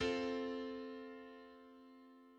C–F–A: